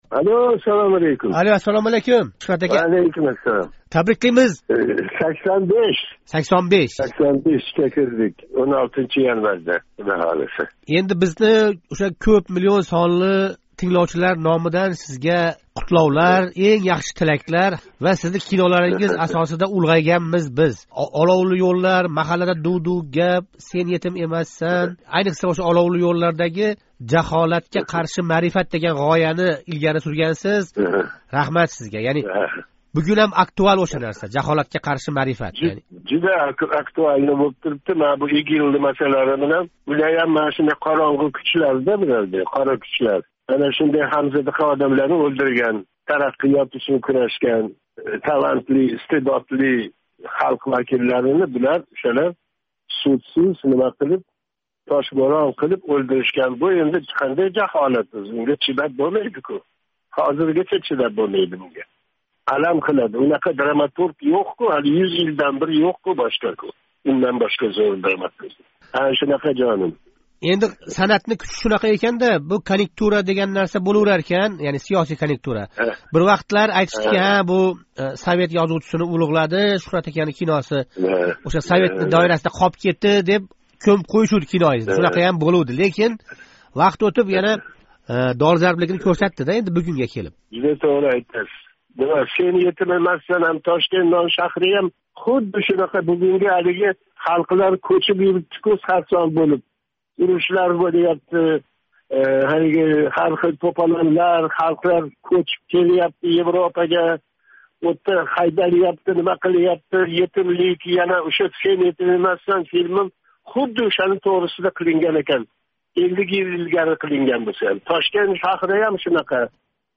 Шуҳрат Аббосов билан суҳбат